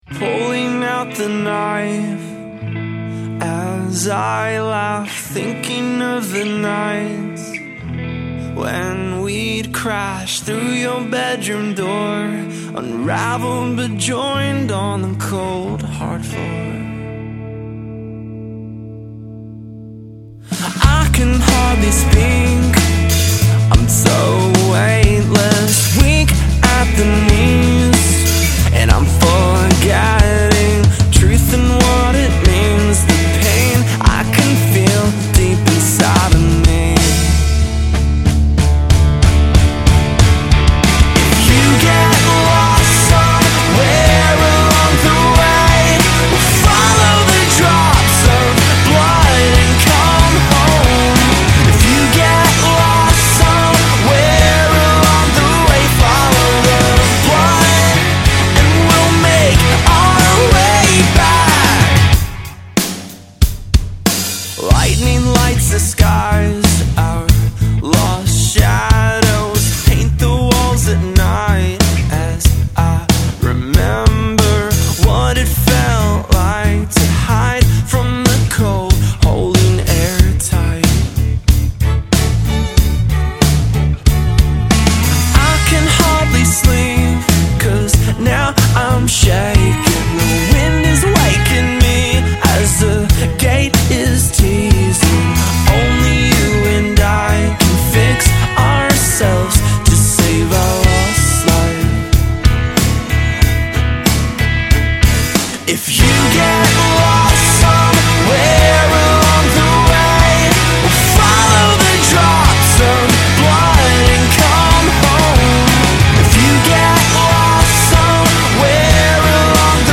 Australian band
five-piece alternative rock band